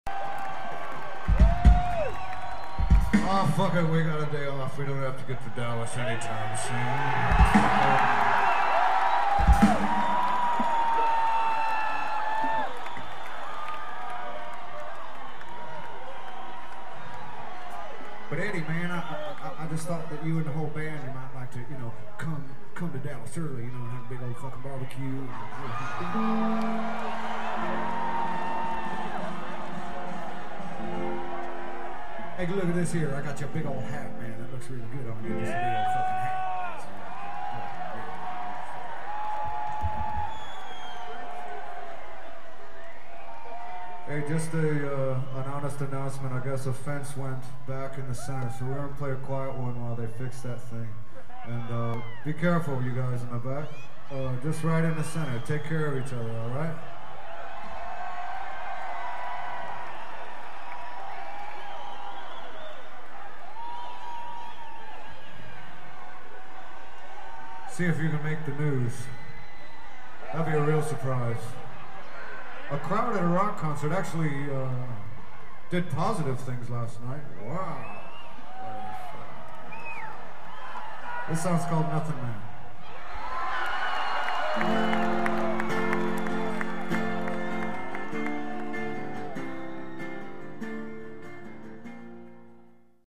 06/29/98 - United Center: Chicago, IL [135m]
He also mentions that he is not that anxious to be getting to Texas quickly, complete with a dead-on impersonation of a Texas drawl.